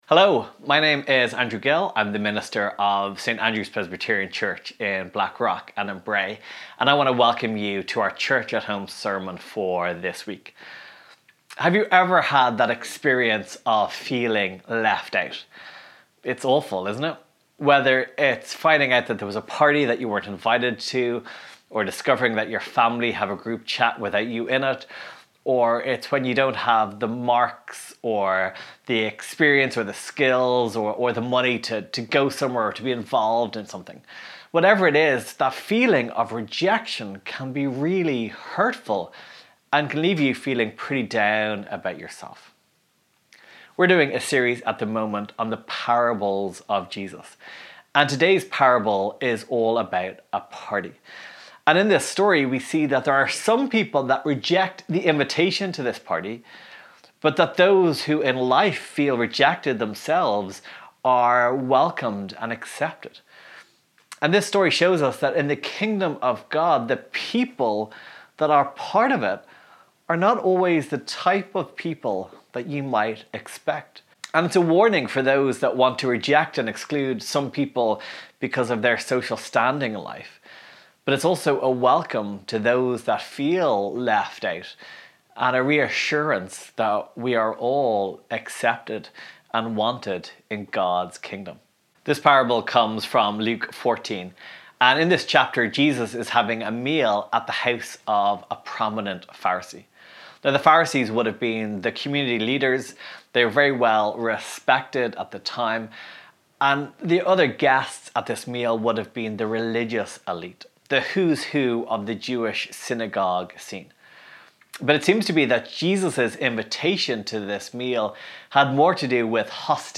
Today we continue our sermon series looking at the Parables of Jesus.